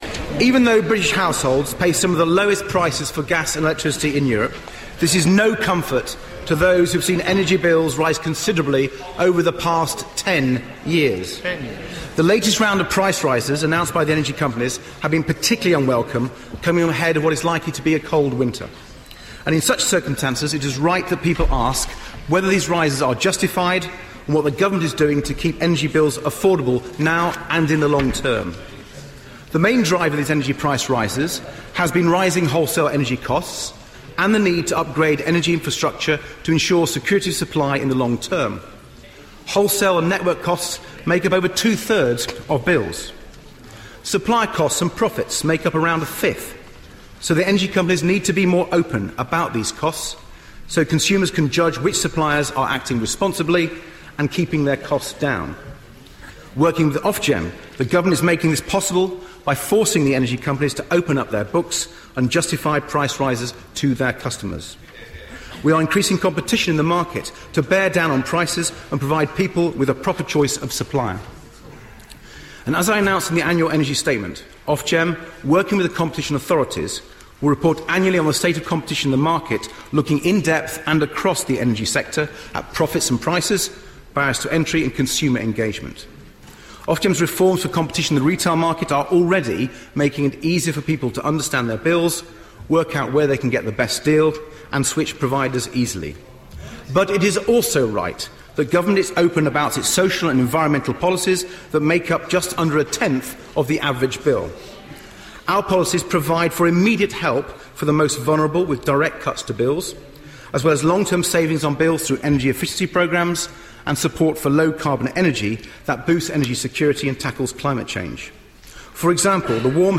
House of Commons, 2 December 2013